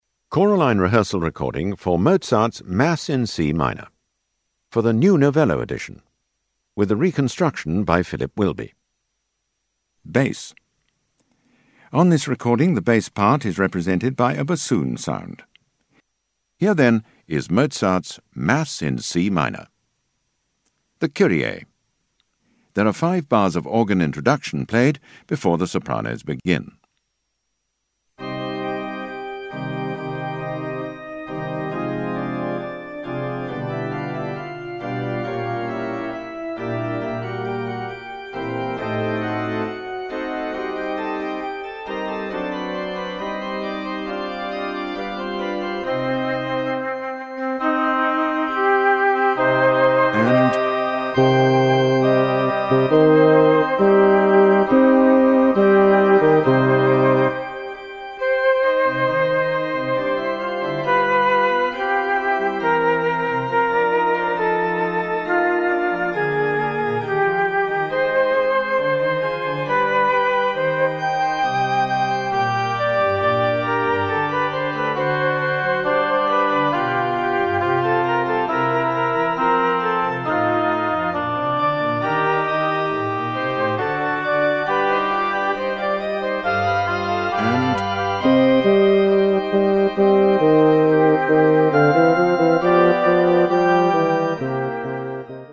Bass
High Quality made by BBC Sound Engineer
Easy To Use narrator calls out when to sing
Don't Get Lost narrator calls out bar numbers
Be Pitch Perfect hear the notes for your part
Vocal Entry pitch cue for when you come in